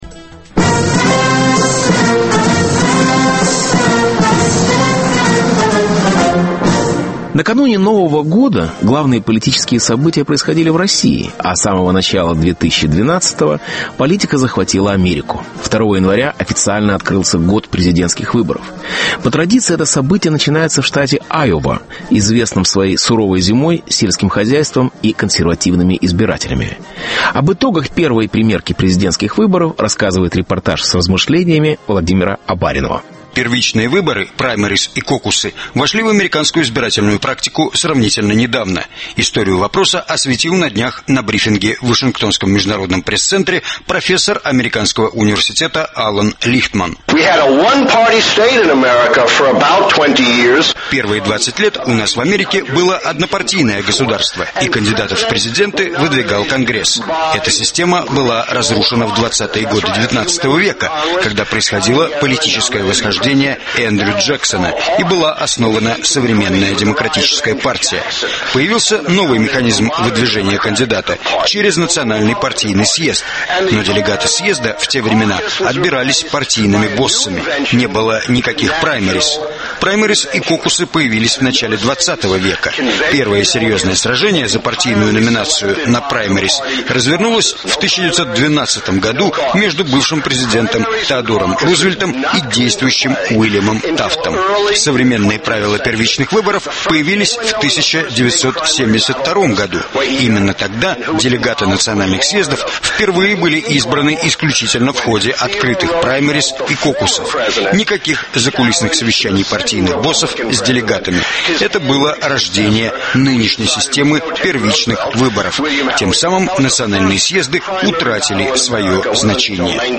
Выборы-2012: Битва за Айову. Репортаж с размышлениями.